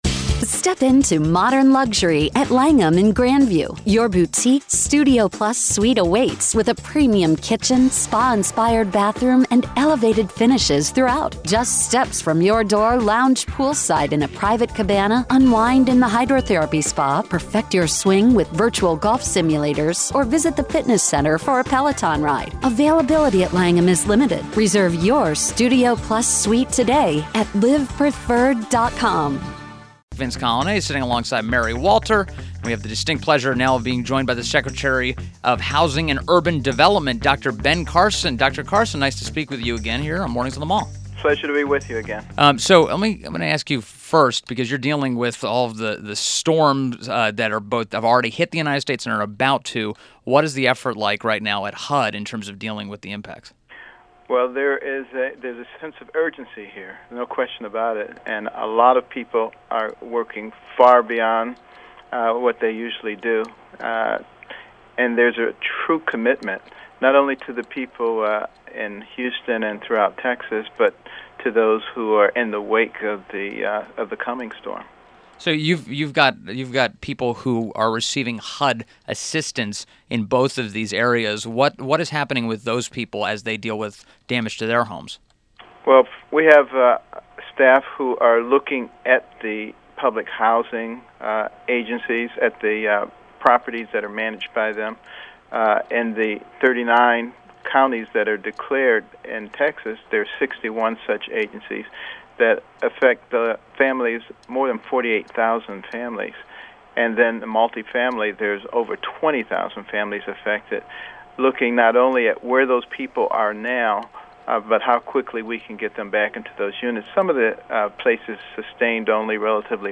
INTERVIEW - DR. BEN CARSON -Secretary of Housing and Urban Development (HUD)